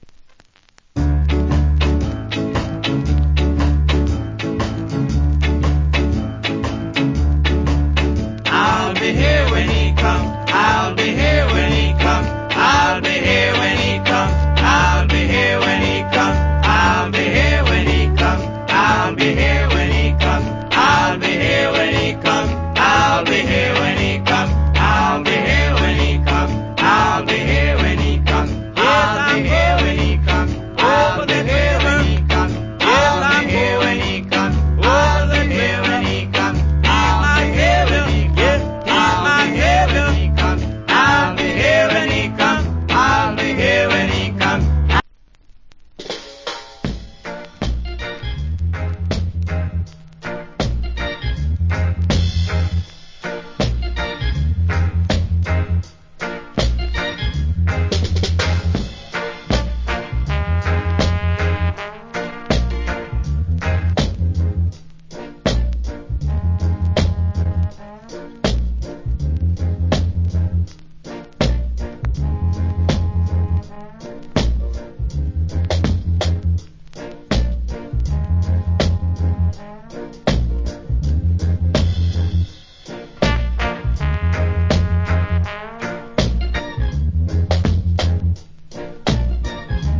Early 60's Shuffle Vocal.